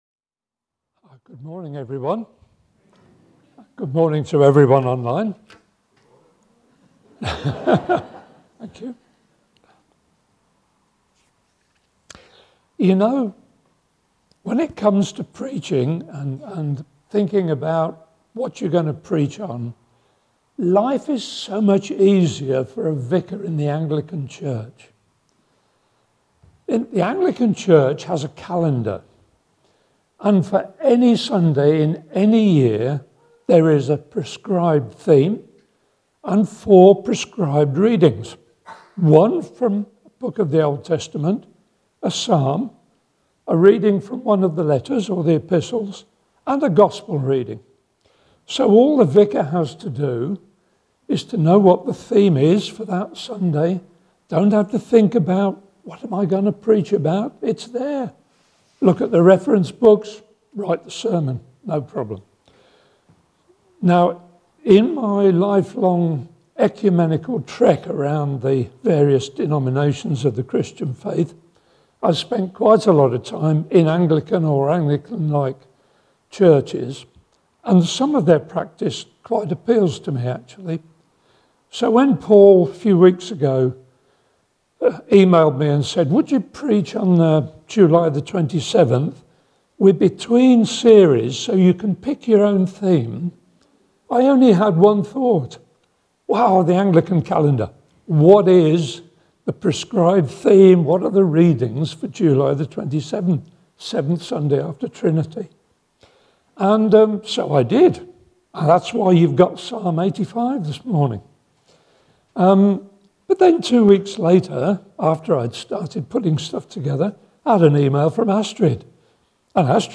Show or hear video or audio of talks or sermons from the Thornhill Baptist Church archives.